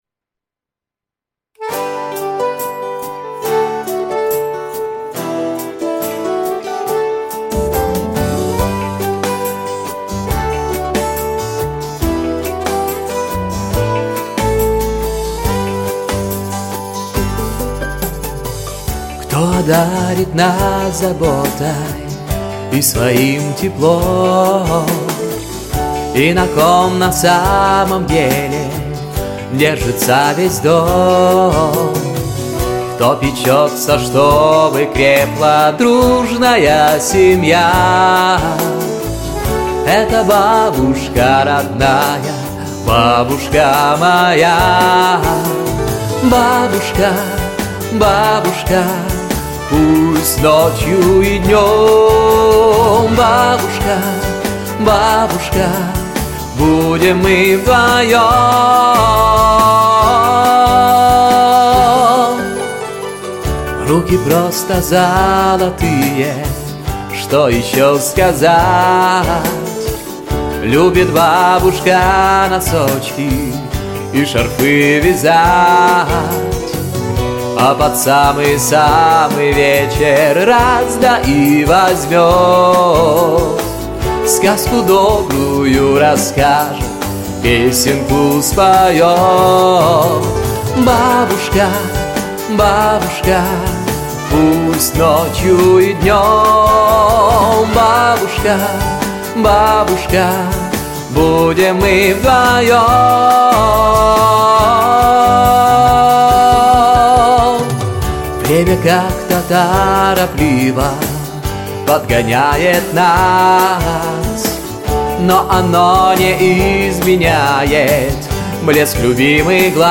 🎶 Детские песни / Песни про бабушку